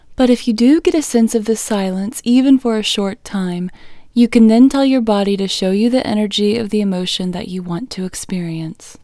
LOCATE IN English Female 34